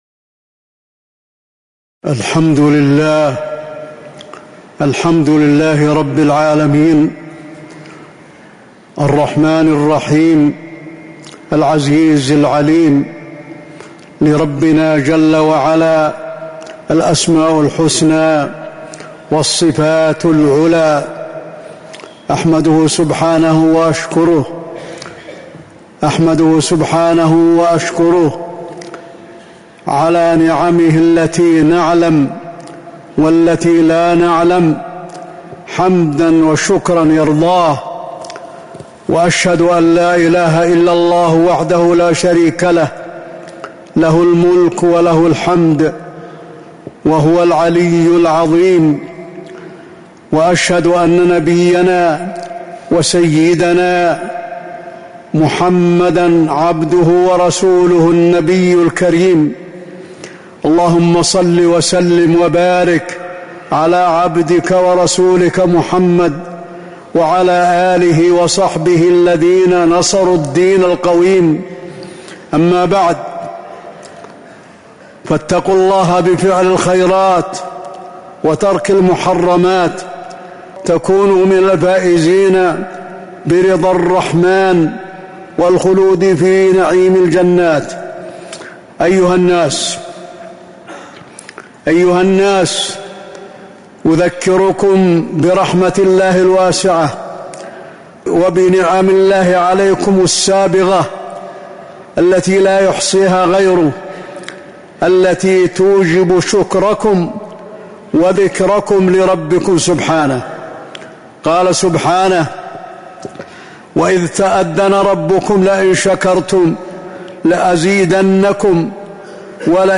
تاريخ النشر ١٦ ذو الحجة ١٤٤٦ هـ المكان: المسجد النبوي الشيخ: فضيلة الشيخ د. علي بن عبدالرحمن الحذيفي فضيلة الشيخ د. علي بن عبدالرحمن الحذيفي من أسباب الرحمة